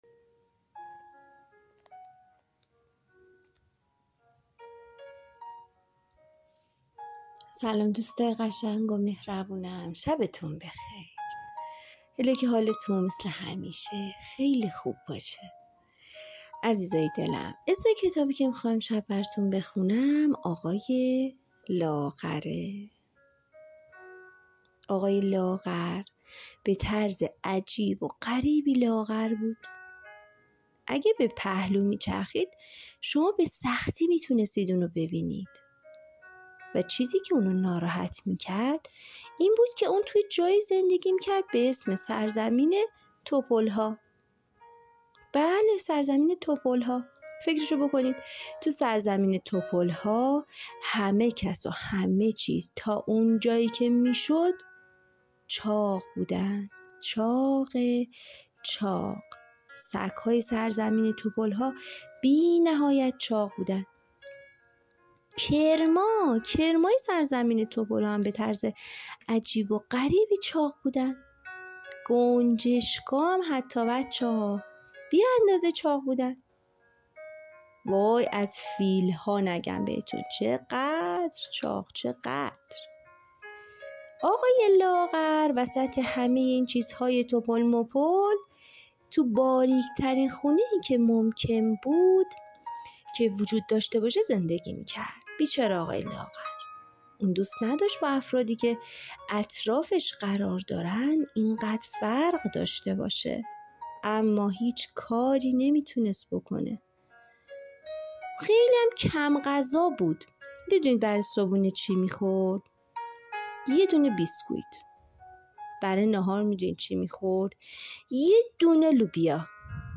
قصه صوتی کودکانه